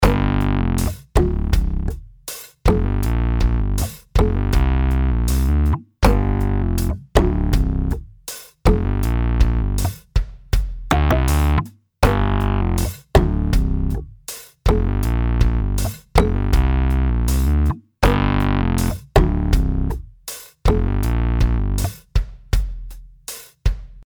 Die Variante aus Keyscape klingt deutlich voller, runder und mit dem Verzerrer auch bissiger als das Original in den Videos:
Die Nebengeräusche der Mechanik habe ich deutlich beigemischt.